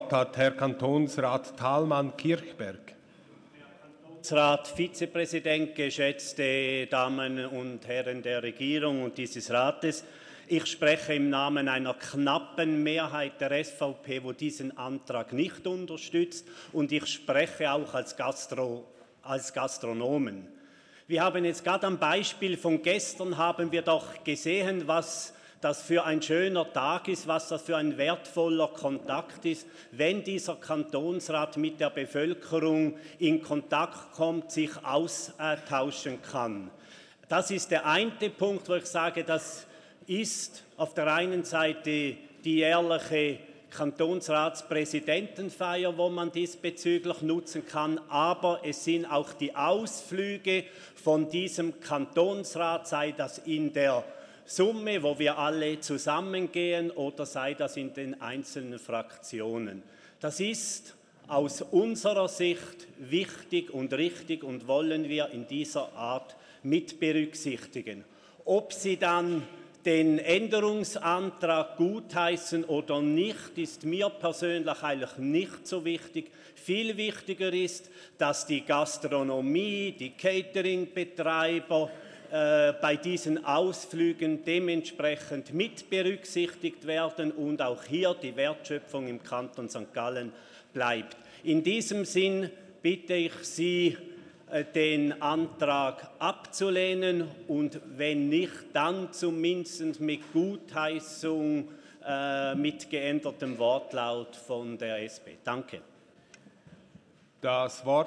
13.6.2019Wortmeldung
(im Namen eine knappen Mehrheit der SVP-Fraktion):
Session des Kantonsrates vom 11. bis 13. Juni 2019